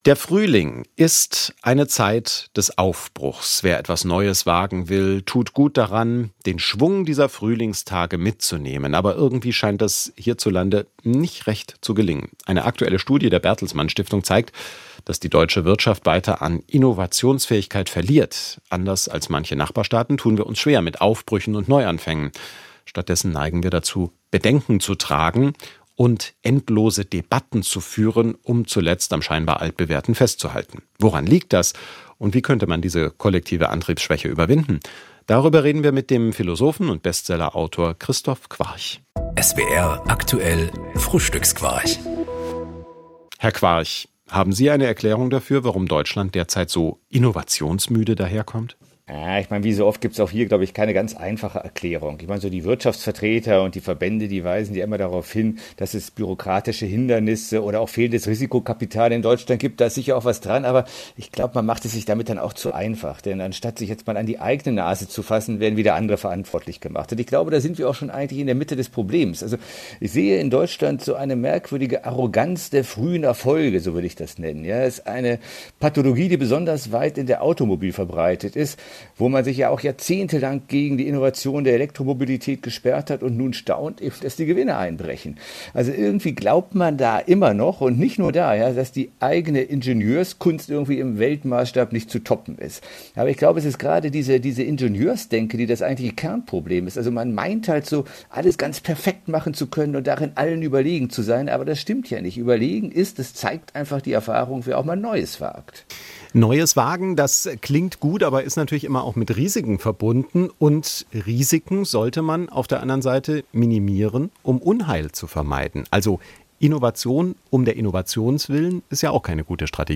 Interviews aus SWR Aktuell als Podcast: Im Gespräch